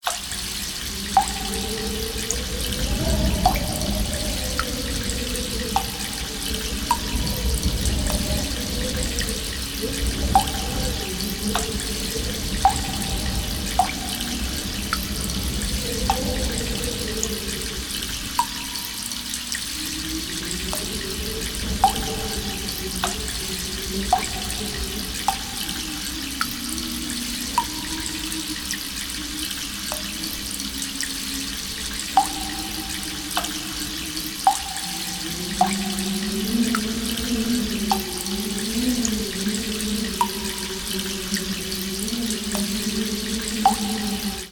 caveambience.mp3